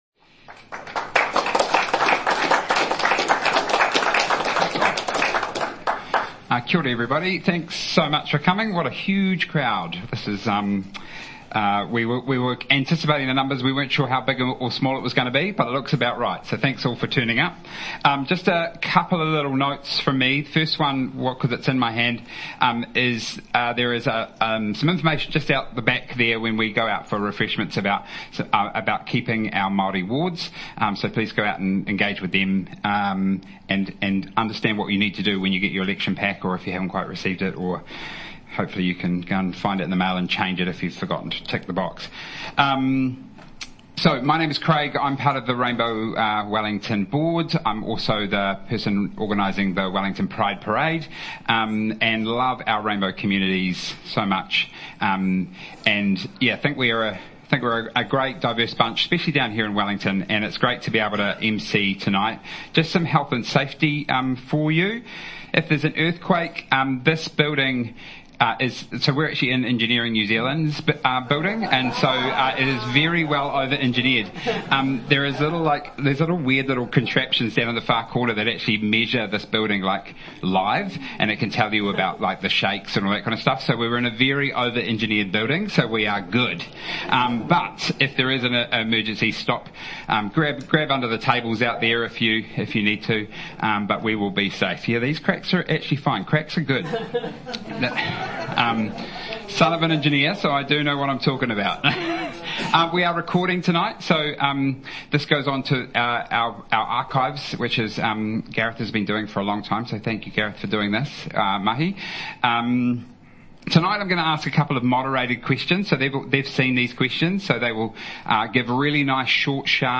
Audio from the Rainbow Wellington Mayoral Candidate Forum, held in Wellington on 24 September 2025.
Questions from the audience